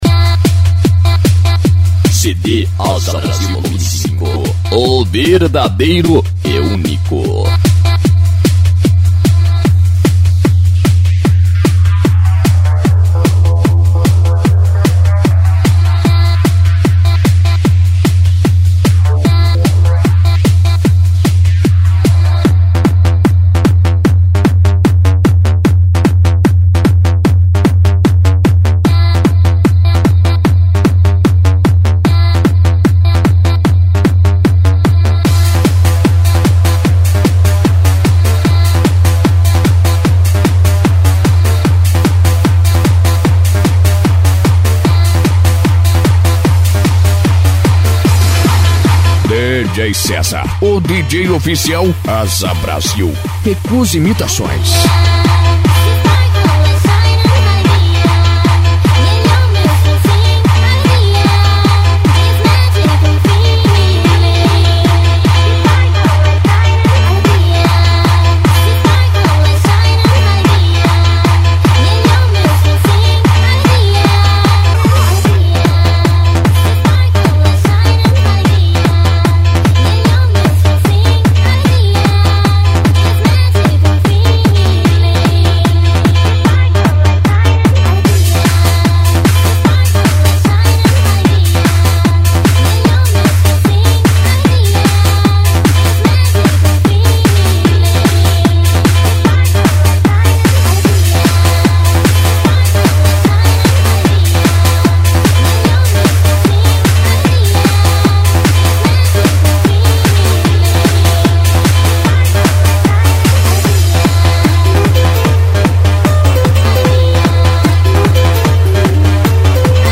PANCADÃO
Funk
Electro House